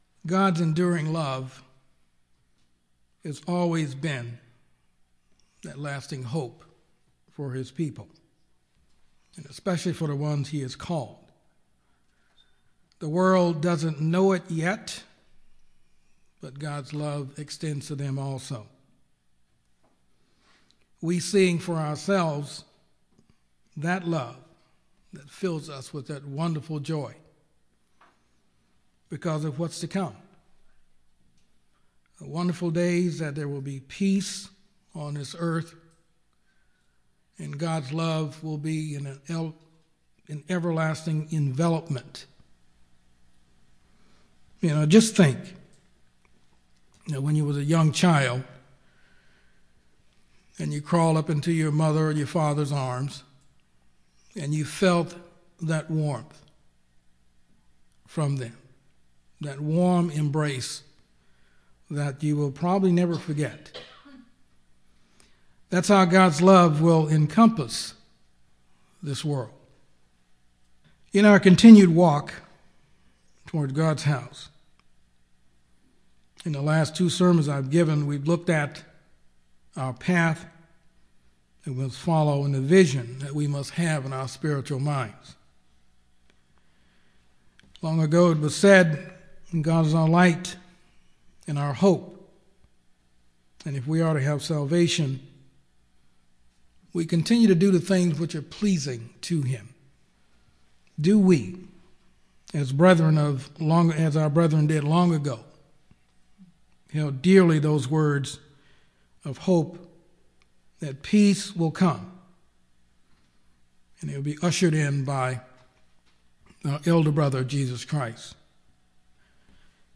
Sermons
Given in Yuma, AZ